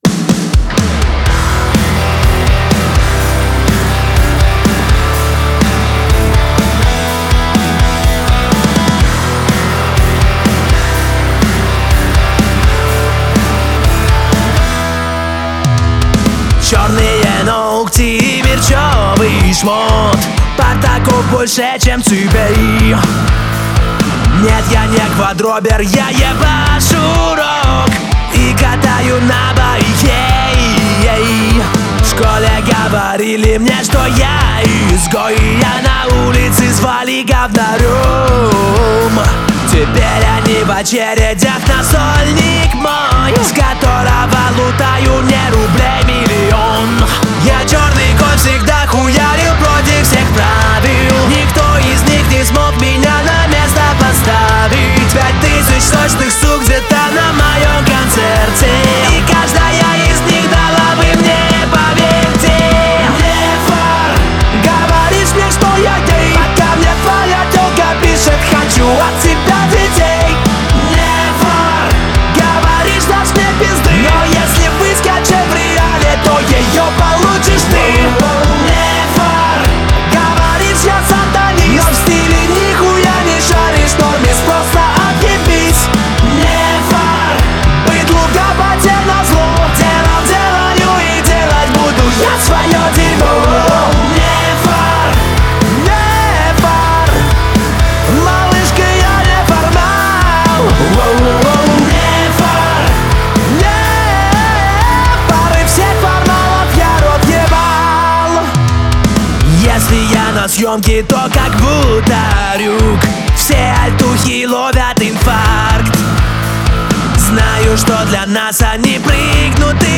• Жанр: Альтернатива, Русская музыка